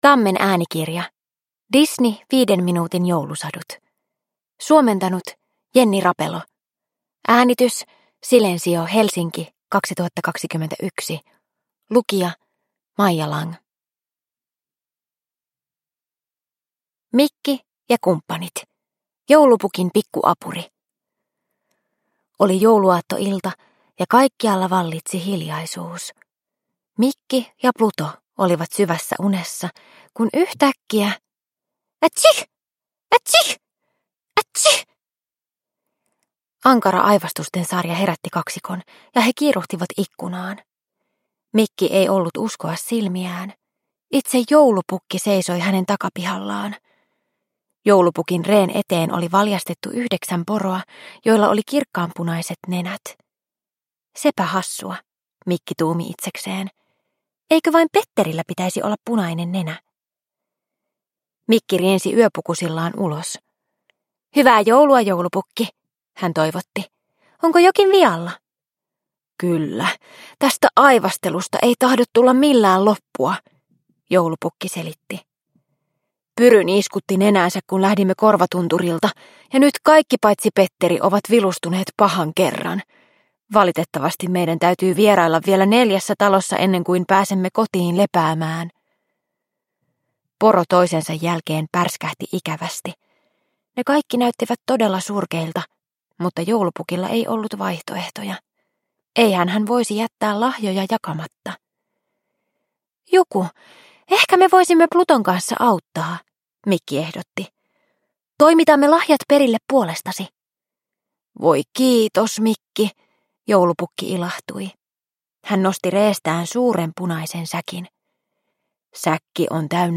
Disney 5 minuutin joulusatuja – Ljudbok – Laddas ner